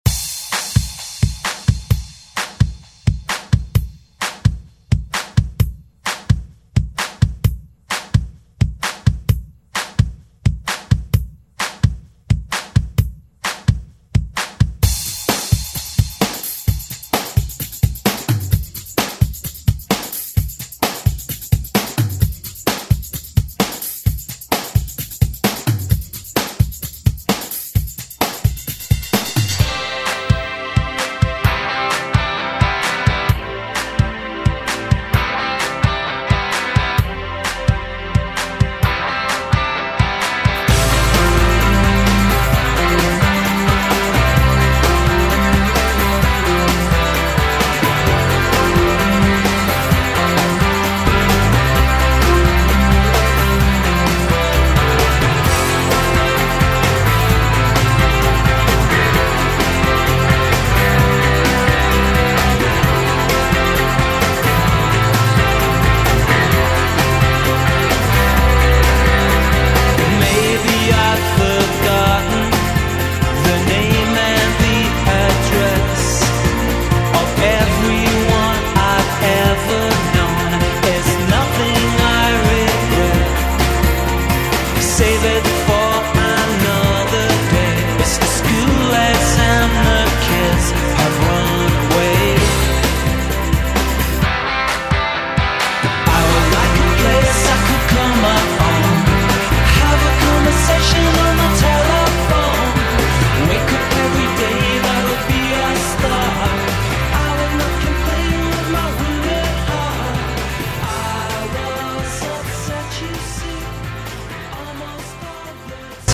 Pop Rock
Extended ReDrum
98 bpm